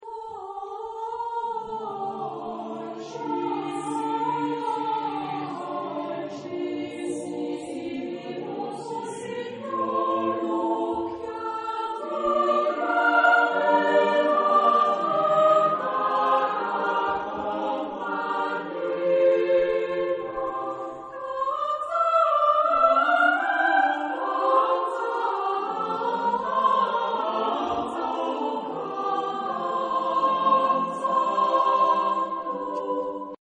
Genre-Style-Forme : Madrigal ; Profane
Type de choeur : SATB  (4 voix mixtes )
Tonalité : ré mineur
Consultable sous : 20ème Profane Acappella